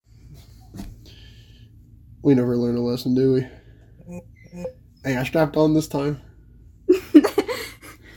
we never learn our lesson Meme Sound Effect